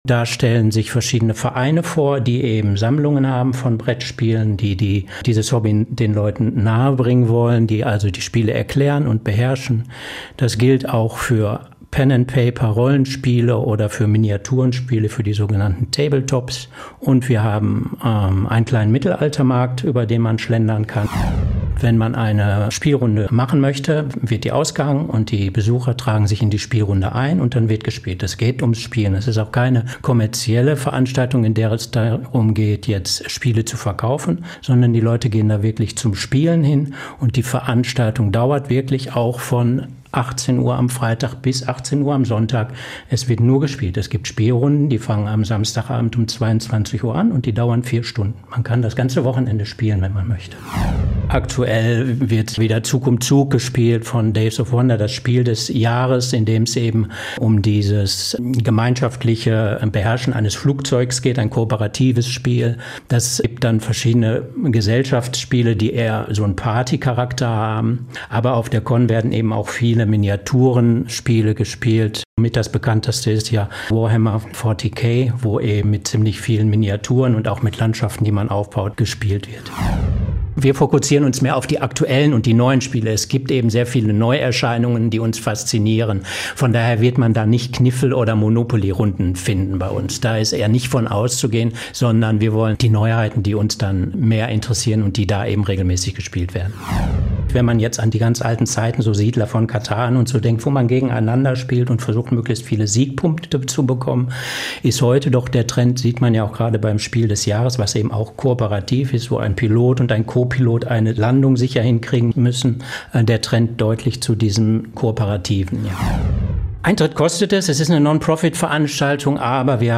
collage-niederrheincon.mp3